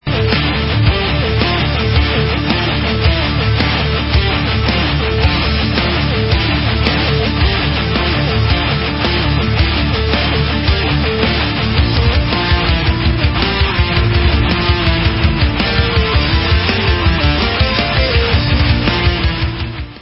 Rock/Progressive